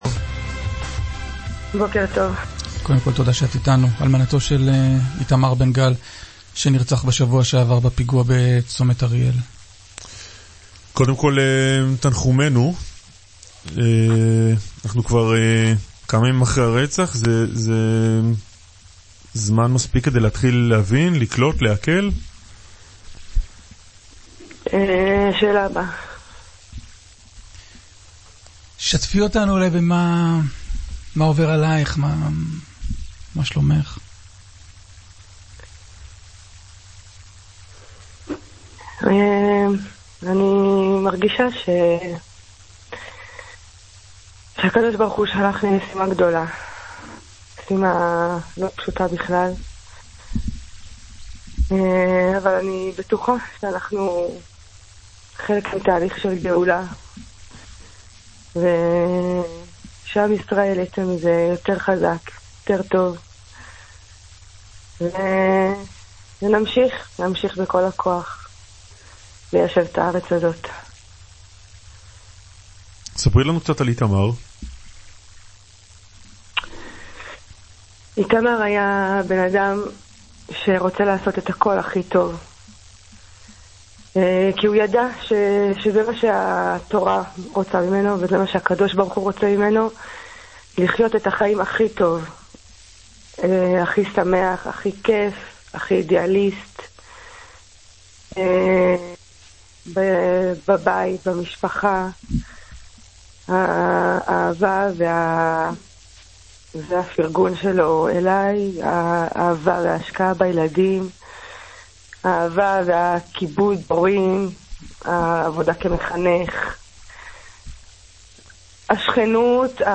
ראיון